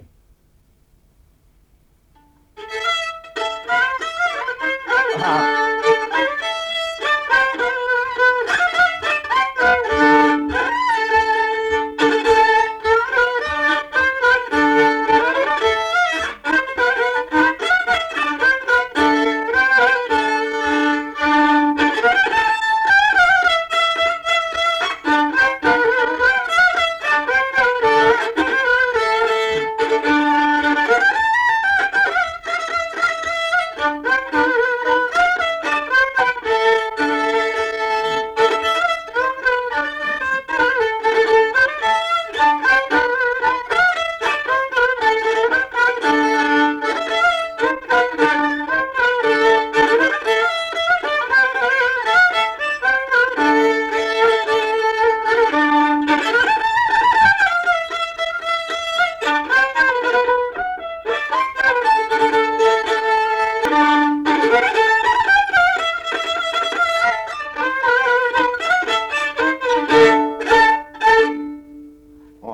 Dalykas, tema šokis
Erdvinė aprėptis Ukmergė
Atlikimo pubūdis instrumentinis
Instrumentas smuikas